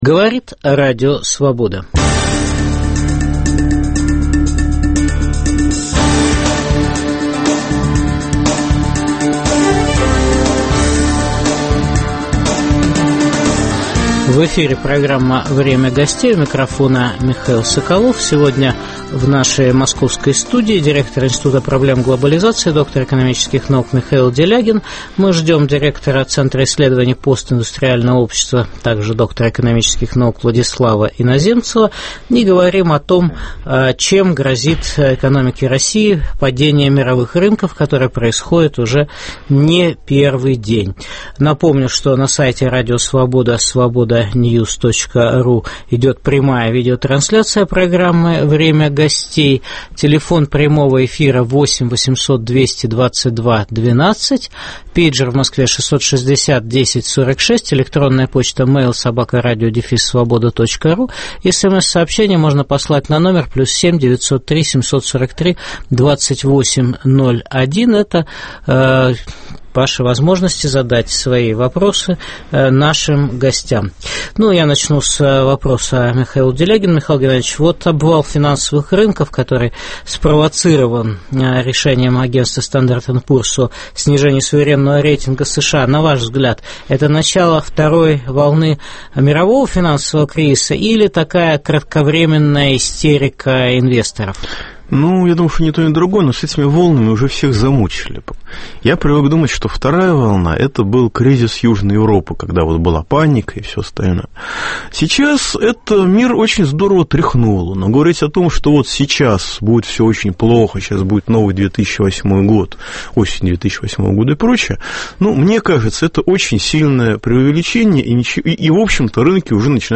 Чем грозит экономике России падение сырьевых рынков? В программе дискутируют директор Центра исследований постиндустриального общества, доктор экономических наук Владислав Иноземцев и директор Института проблем глобализации, доктор экономических наук Михаил Делягин.